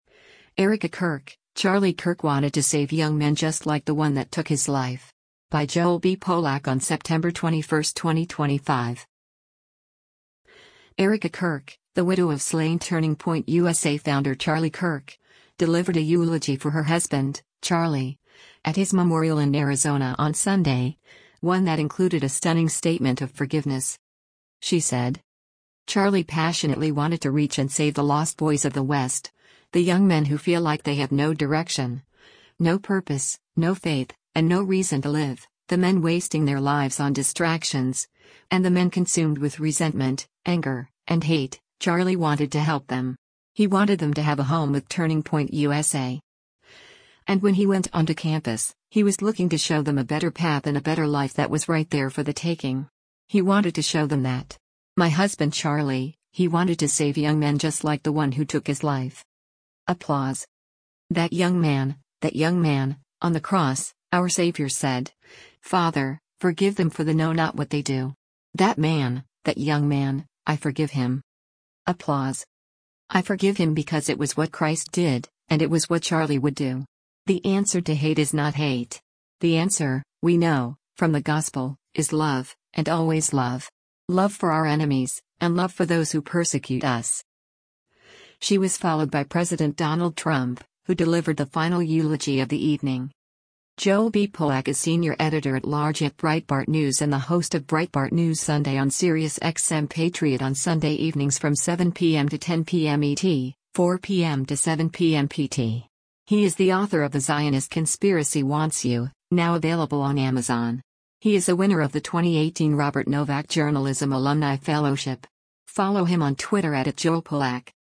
Erika Kirk, the widow of slain Turning Point USA founder Charlie Kirk, delivered a eulogy for her husband, Charlie, at his memorial in Arizona on Sunday — one that included a stunning statement of forgiveness.